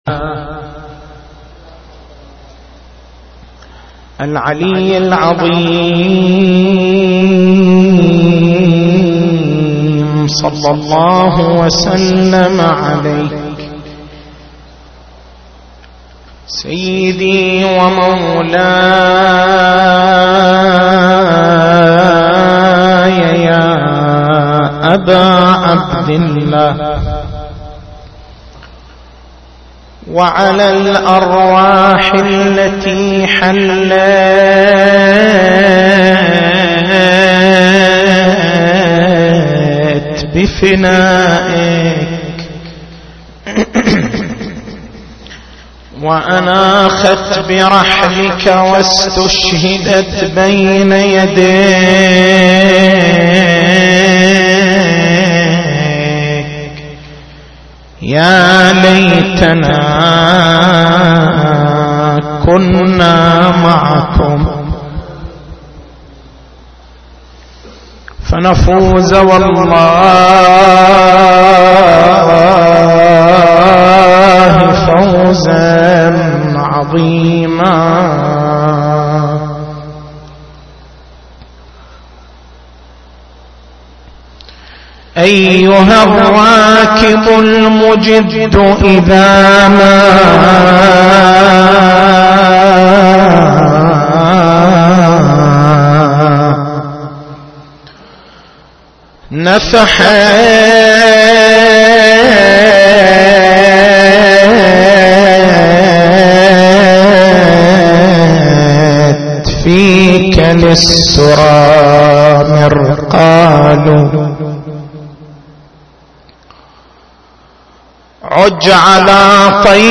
تاريخ المحاضرة